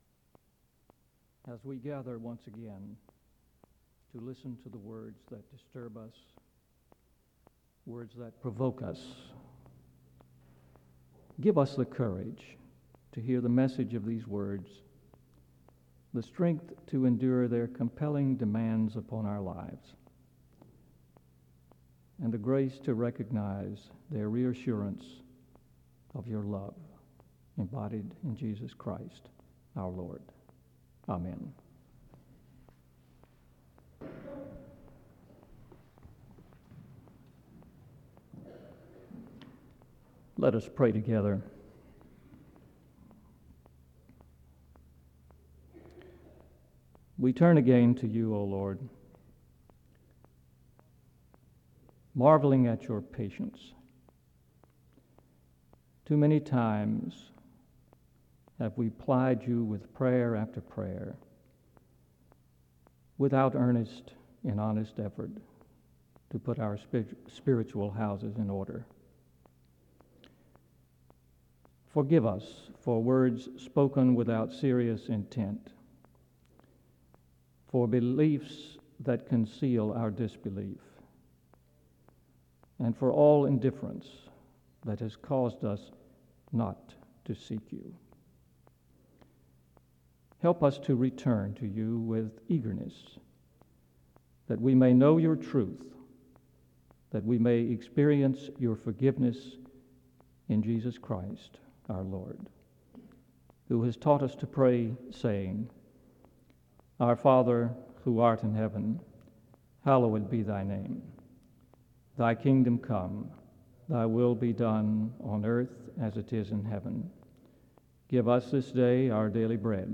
The service opens with a prayer from 0:00-0:31. A second prayer is offered from 0:40-2:18. Luke 18:18-23 and Mark 14:3-9 are read from 2:26-4:37.